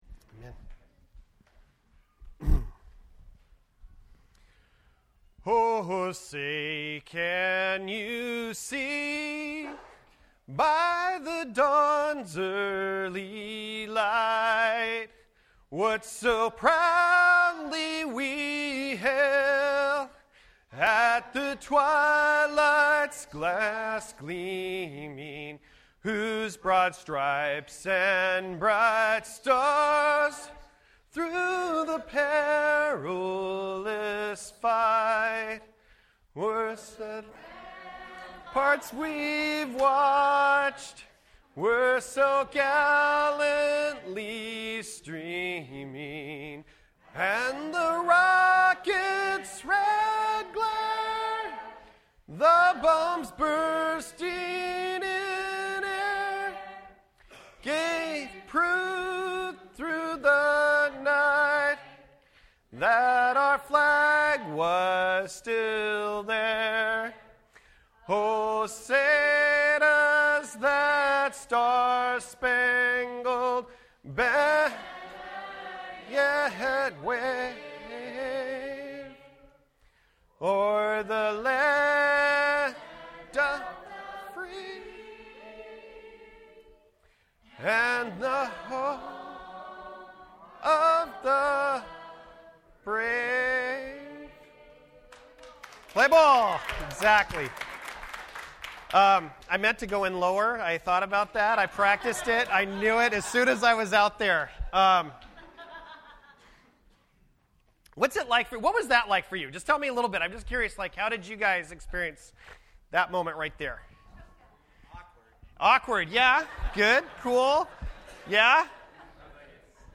Series: FWC Sermons Service Type: Sunday Morning %todo_render% Related « Growing Beyond the Past The Core